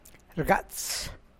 rgats[rgàa’ts]